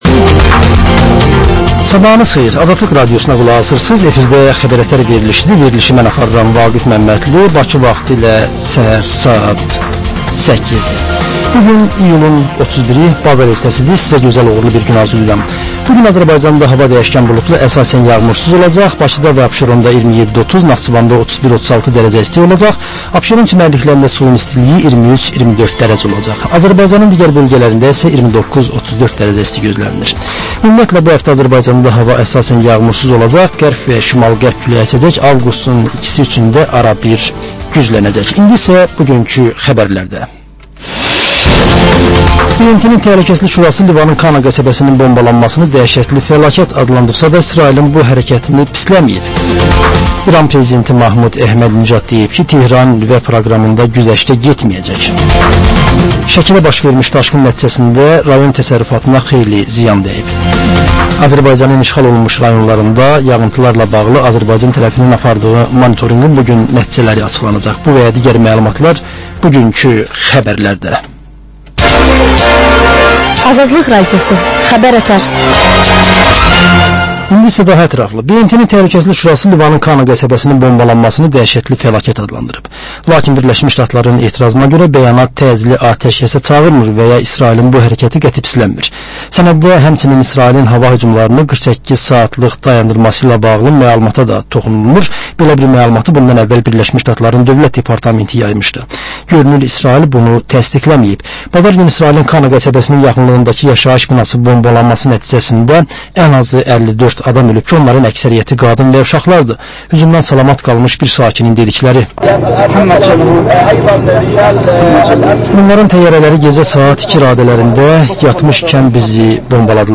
Səhər-səhər, Xəbər-ətər: xəbərlər, reportajlar, müsahibələr.